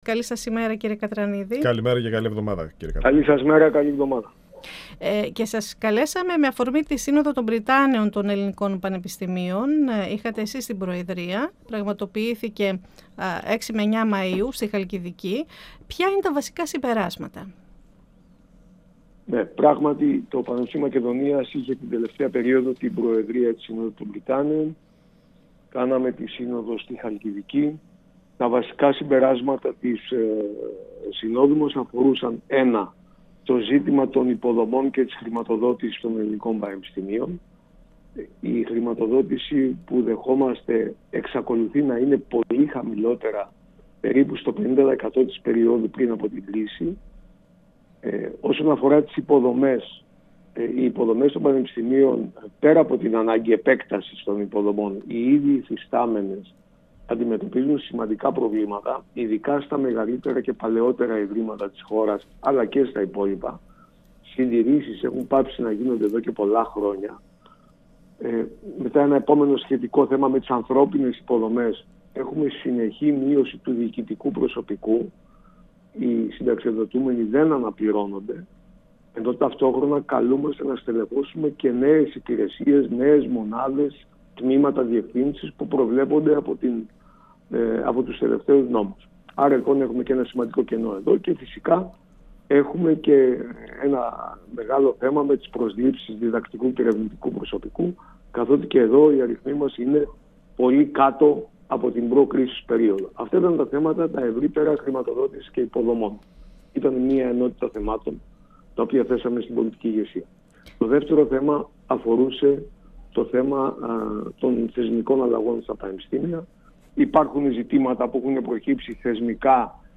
Στα βασικά συμπεράσματα της Συνόδου των Πρυτάνεων στη Χαλκιδική αναφέρθηκε σε συνέντευξή του στην «Αίθουσα Σύνταξης» του 102FM της ΕΡΤ3 ο πρύτανης του Πανεπιστημίου Μακεδονίας, Στυλιανός Κατρανίδης.
Σε ό,τι αφορά το ζήτημα των διαγραφών, επισήμανε ότι πρέπει να υπάρχει διάκριση ανάμεσα σε ενεργούς και μη ενεργούς φοιτητές. 102FM Αιθουσα Συνταξης Συνεντεύξεις ΕΡΤ3